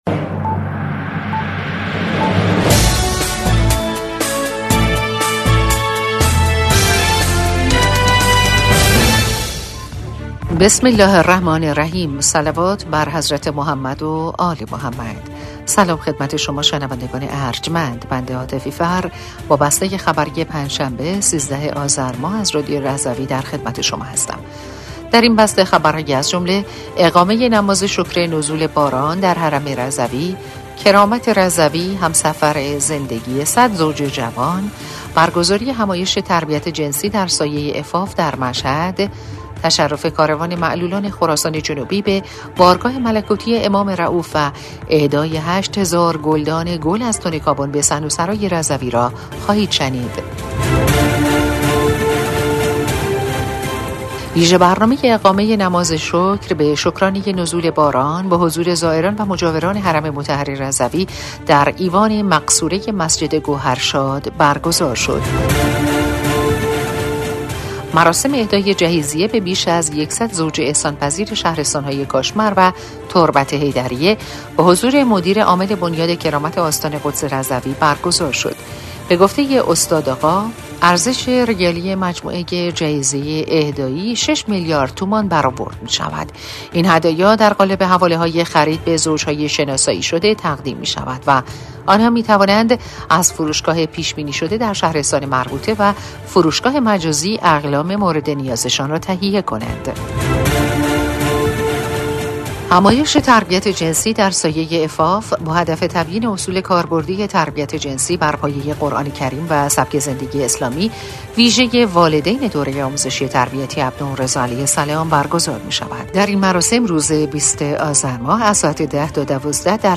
بسته خبری ۱۳ آذر ۱۴۰۴ رادیو رضوی؛